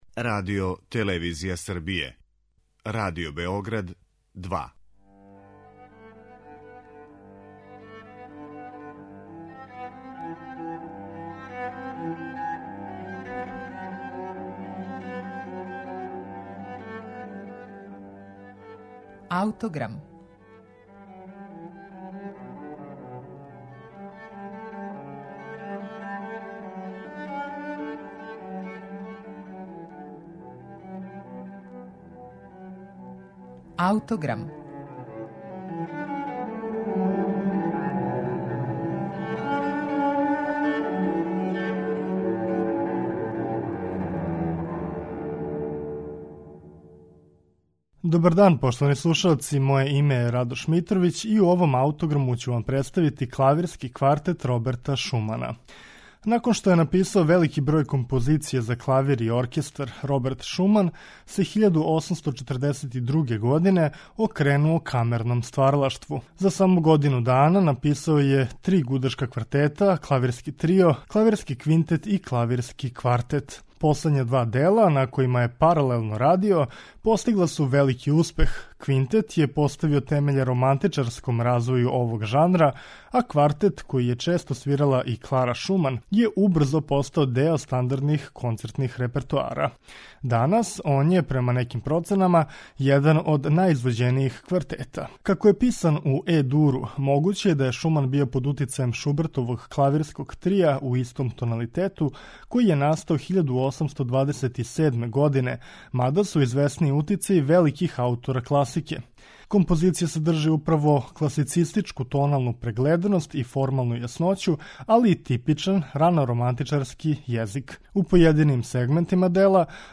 Клавирски квартет Роберта Шумана
Клавирски квартет Роберта Шумана слушаћете у извођењу Менахема Преслера и гудачког квартета Емерсон.